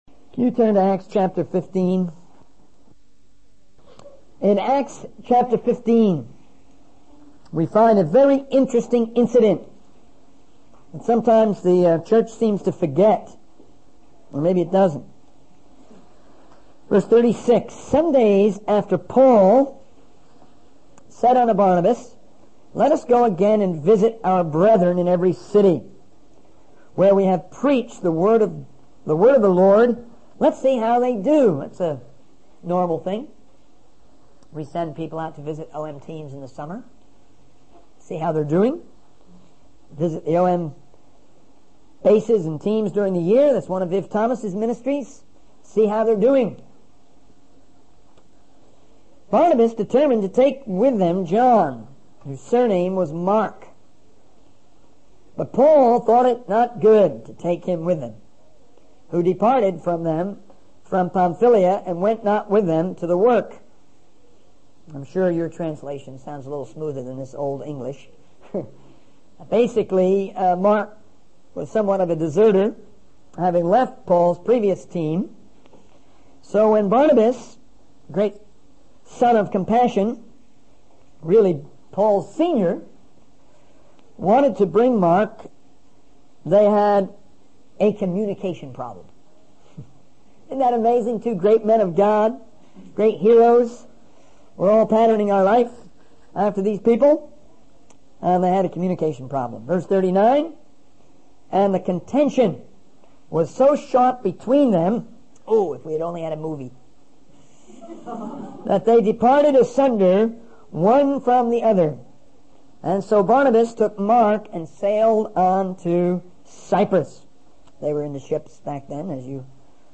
In this sermon, the speaker discusses four enemies that can hinder our communication and relationships.